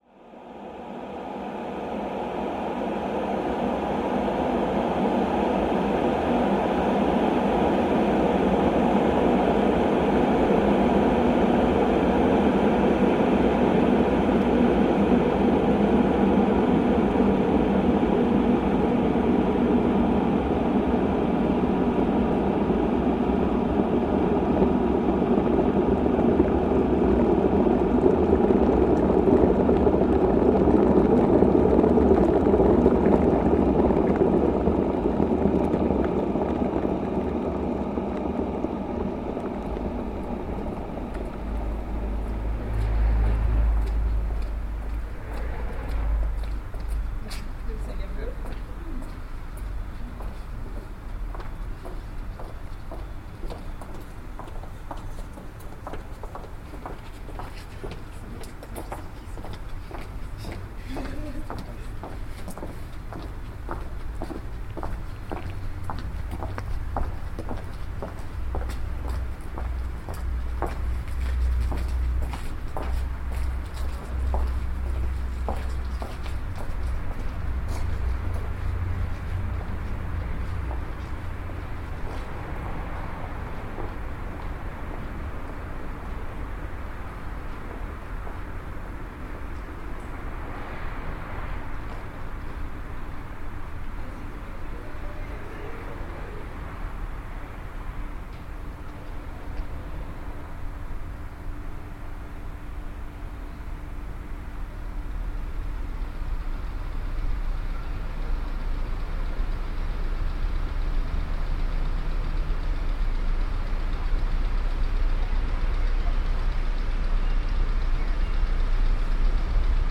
These are field recordings from a trip to Krakow / Auschwitz-Birkenau.
phonography / field recording; contextual and decontextualized sound activity